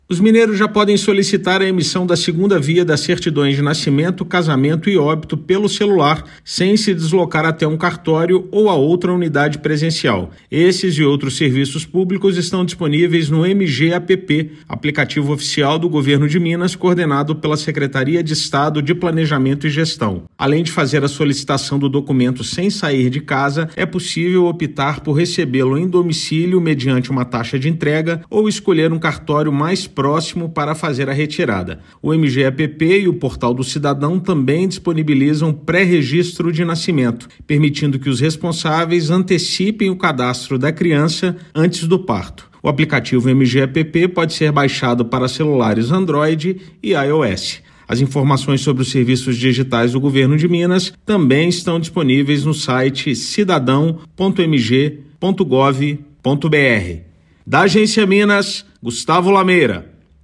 Solicitante não precisa se deslocar até uma unidade presencial para pedir o documento, podendo recebê-lo em casa ou no cartório de sua escolha. Ouça matéria de rádio.